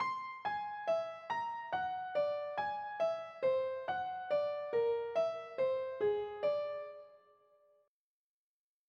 whole tone practice exercise
The third exercise is particularly interesting because it creates a hemiola. This makes the exercise more rhythmically interesting.
whole-tone-ex-three.mp3